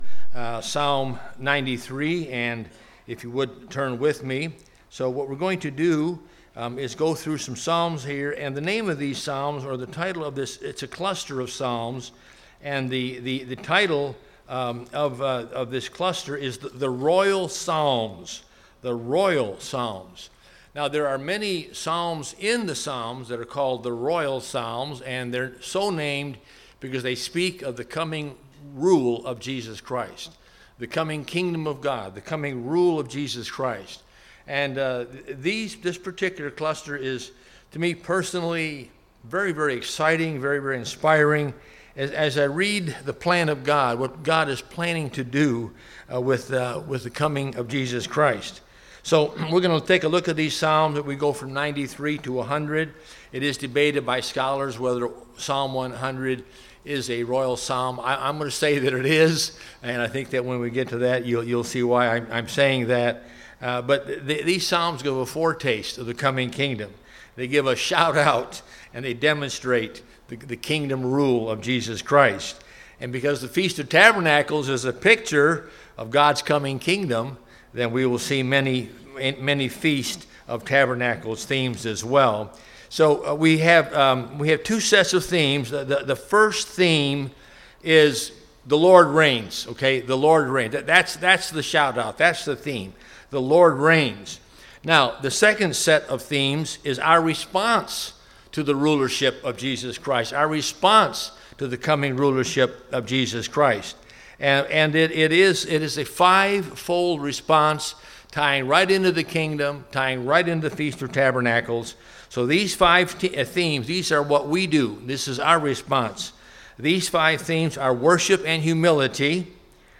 This sermon was given at the Anchorage, Alaska 2021 Feast site.